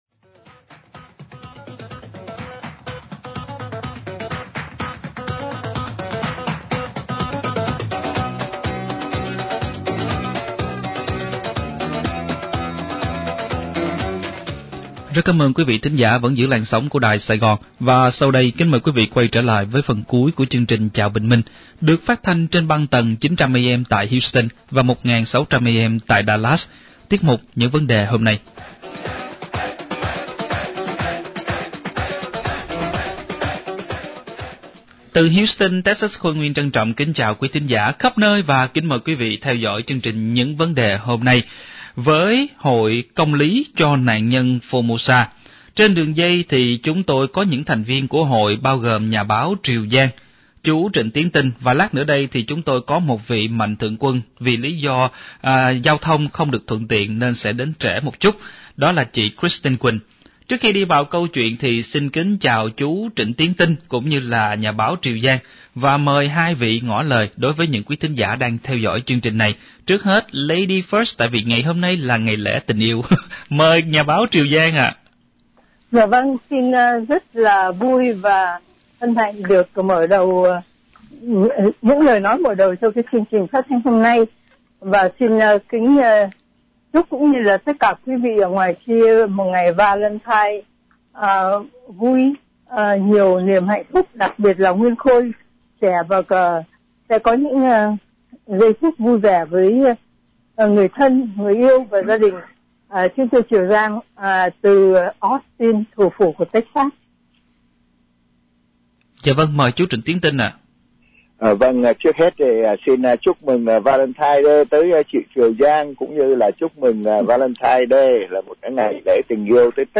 Phỏng vấn của Đài Sàigòn Houston Radio 900AM về Buổi Dạ Tiệc Gây Quỹ Công Lý Cho Nạn Nhân Formosa Tại Houston Ngày 10 Tháng 5 năm 2020